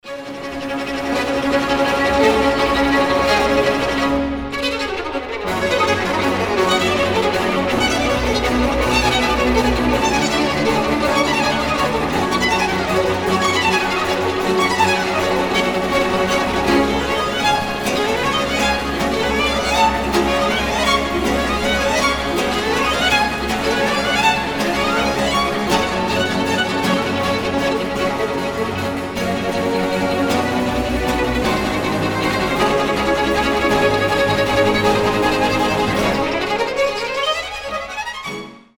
• Качество: 320, Stereo
без слов
скрипка
быстрые
оркестр
тревога
Известная классическая скрипичная музыка